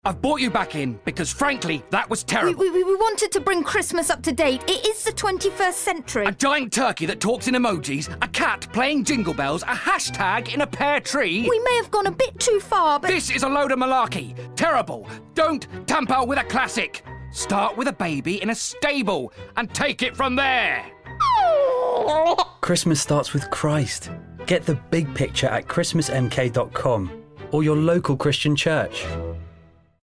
Christmas radio advert from Churches in Milton Keynes